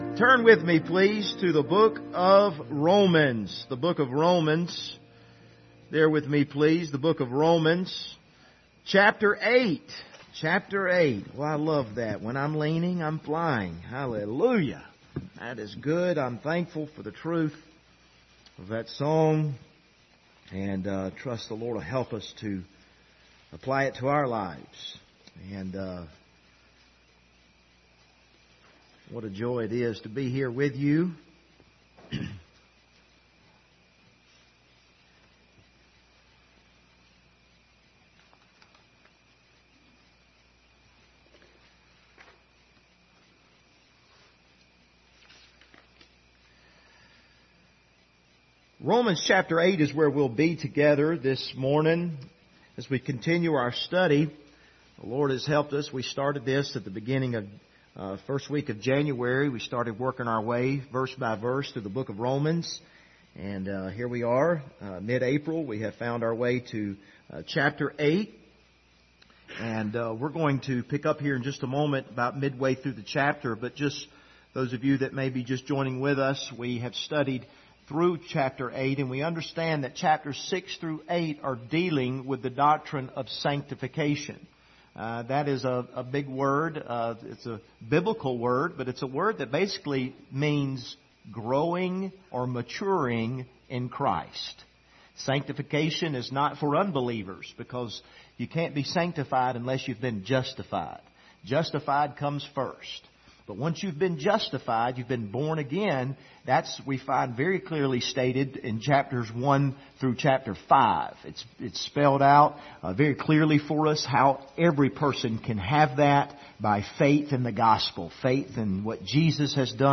Passage: Romans 8:12-13 Service Type: Sunday Morning